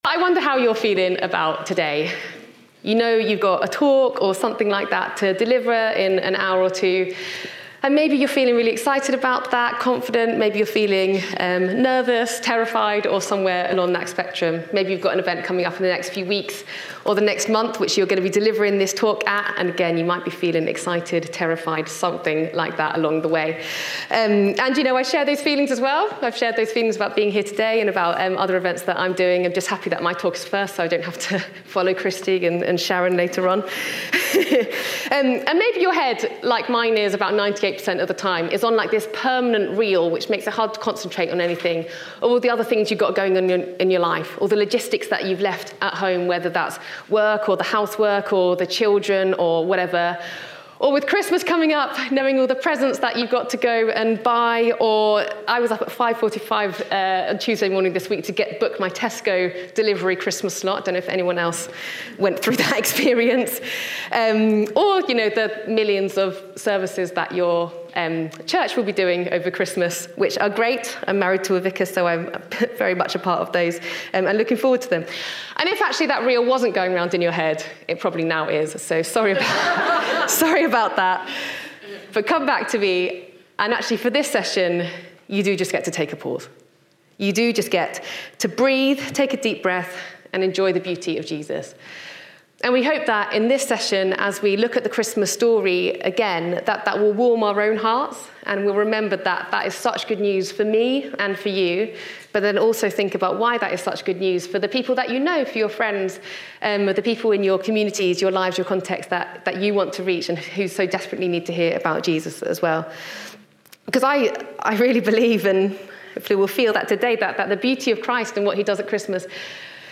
Chapel Talks